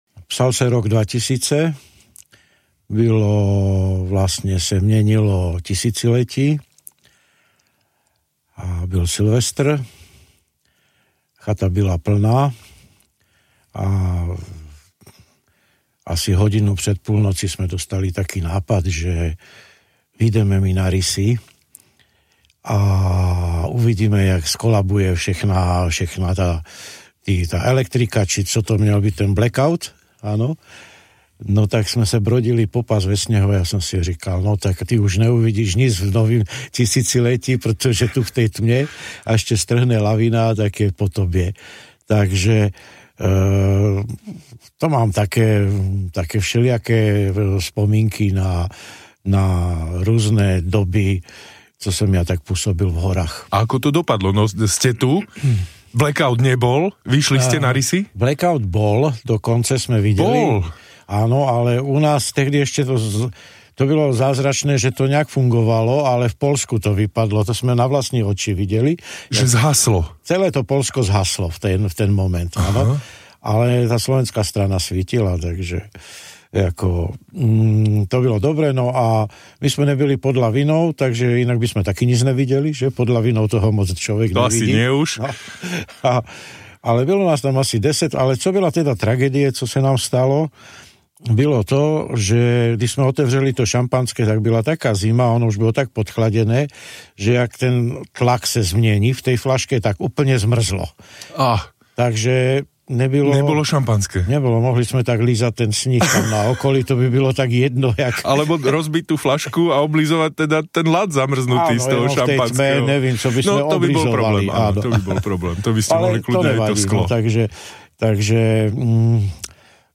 Netradiční hostia a zaujímavé rozhovory pokračujú aj počas zimnej prestávky.